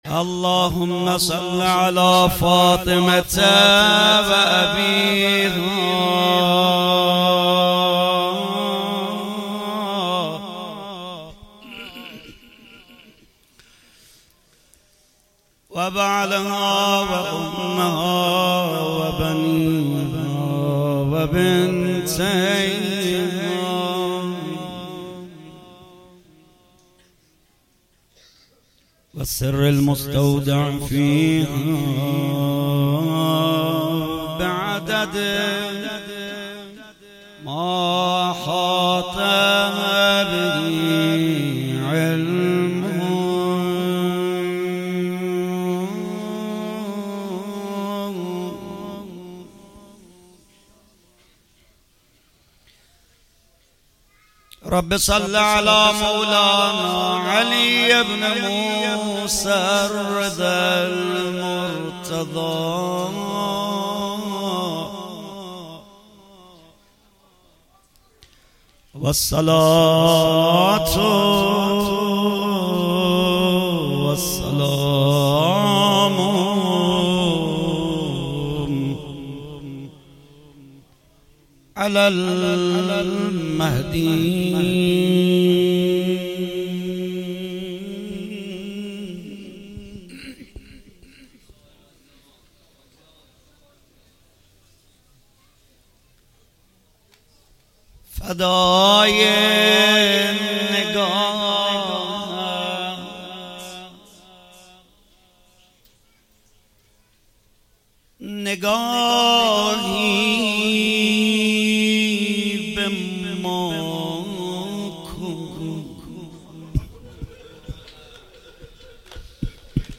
استقبال فاطمیه 96 - مشهد - روضه حضرت زهرا سلام الله علیها